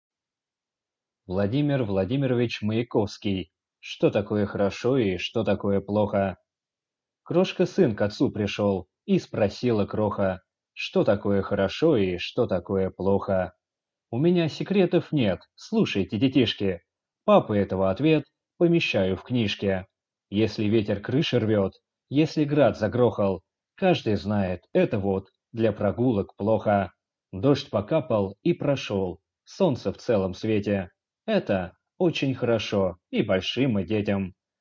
Аудиокнига Что такое хорошо и что такое плохо?